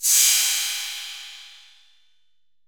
808MP49CYM.wav